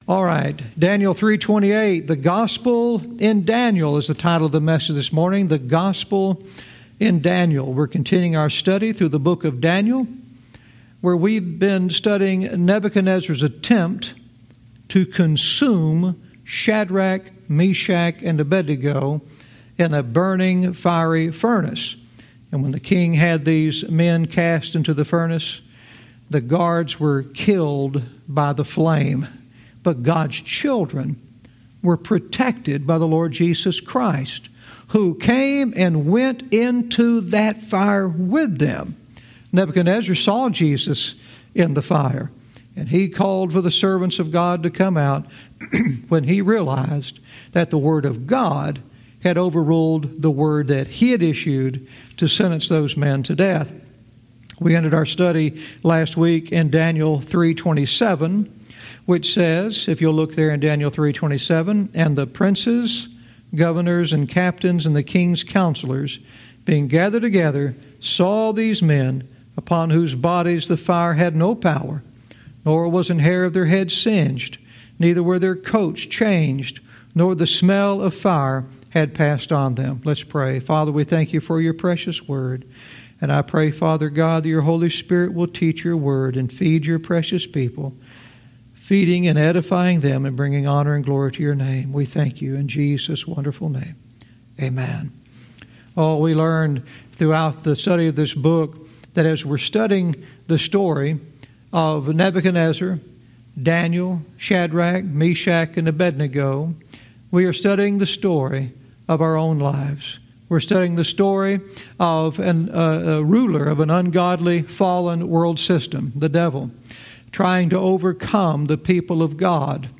Verse by verse teaching - Daniel 3:28 "The Gospel in Daniel"